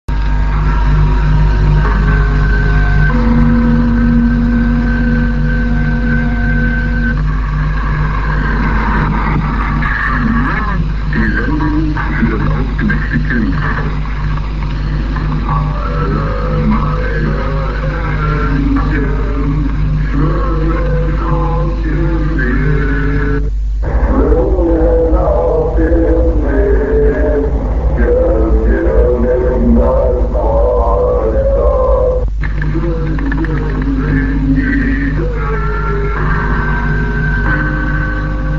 Instead of the usual message structure, we instead have a male announcer say "And now the transmission for the bright child," followed by a bunch of drunk NVA soldiers singing a children's song, "All My Ducklings."